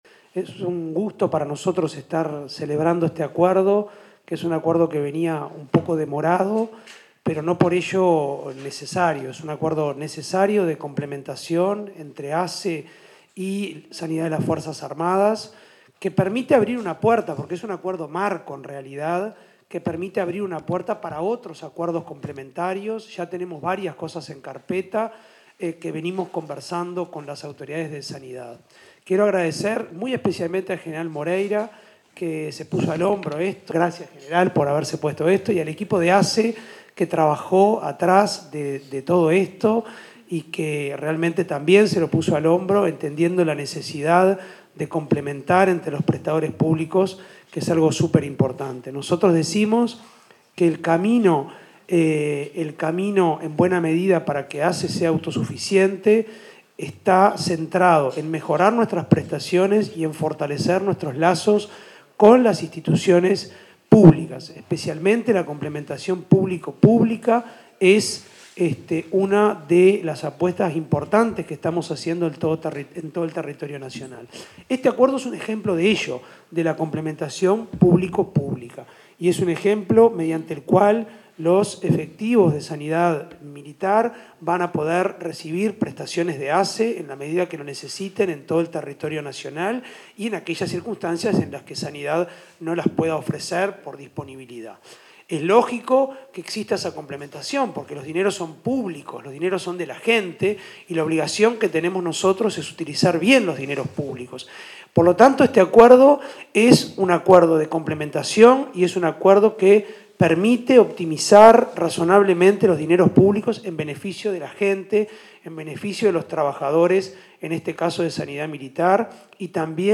Palabras de autoridades en firma de convenio entre ASSE y Sanidad Militar
Palabras de autoridades en firma de convenio entre ASSE y Sanidad Militar 03/06/2025 Compartir Facebook X Copiar enlace WhatsApp LinkedIn Durante la firma de un convenio entre la Administración de los Servicios de Salud del Estado (ASSE) y la Dirección Nacional de Sanidad Militar de las Fuerzas Armadas, se expresaron el presidente de ASSE, Álvaro Danza, y las ministras de Defensa Nacional, Sandra Lazo, y Salud Pública, Cristina Lustemberg.